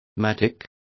Also find out how azadón is pronounced correctly.